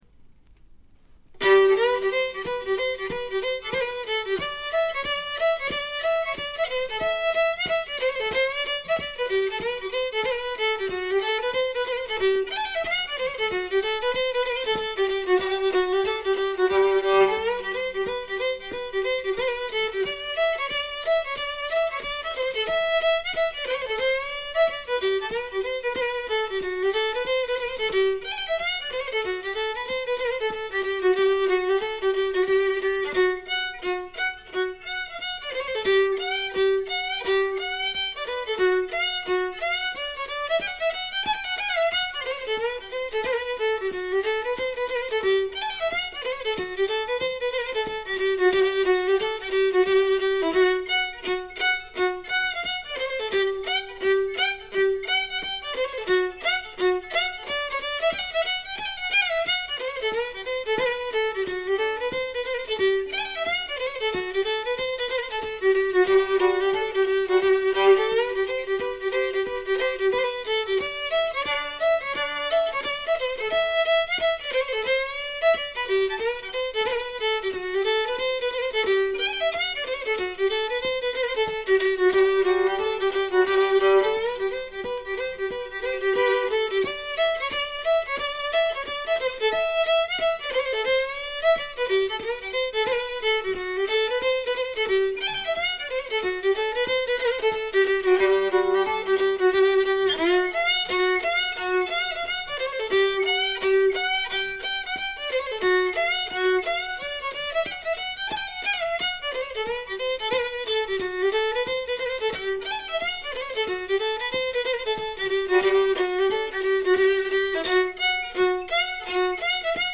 ところどころでバックグラウンドノイズ、チリプチ。散発的なプツ音少々。
試聴曲は現品からの取り込み音源です。
Fiddle